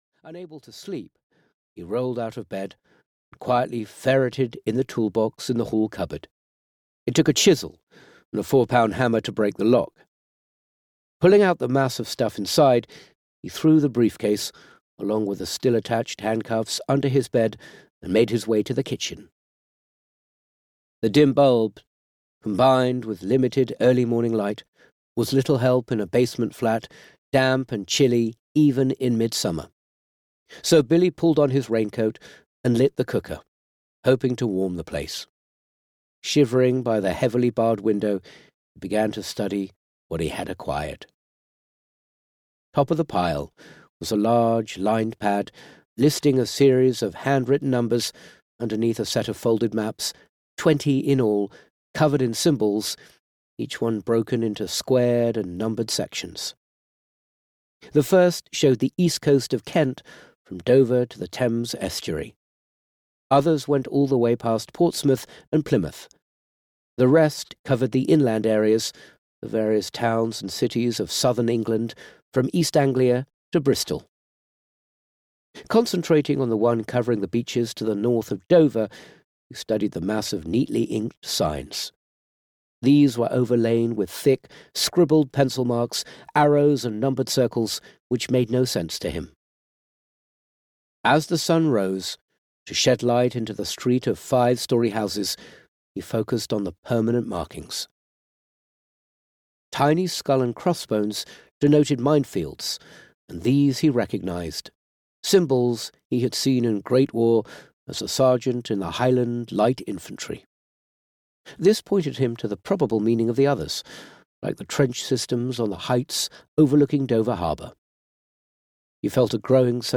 Every Second Counts (EN) audiokniha
Ukázka z knihy